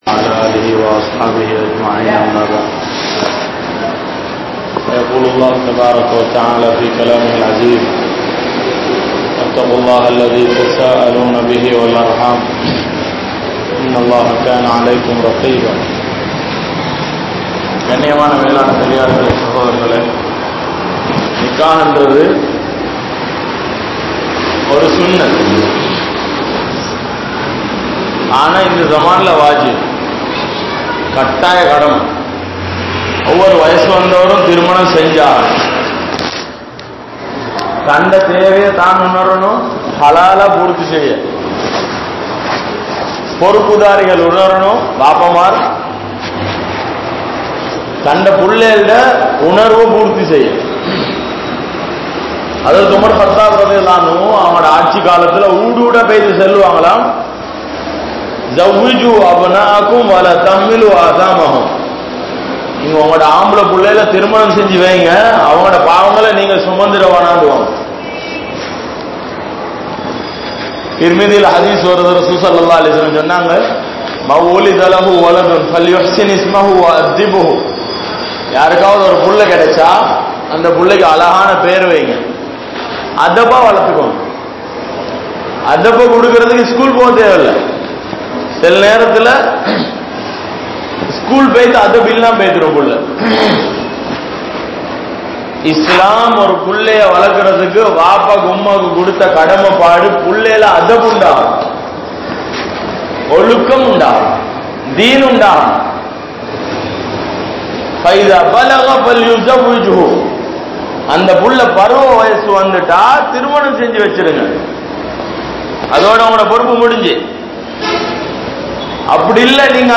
Paavaththil Valarum Vaalifarhal (பாவத்தில் வளரும் வாலிபர்கள்) | Audio Bayans | All Ceylon Muslim Youth Community | Addalaichenai